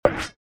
cancelMenu.mp3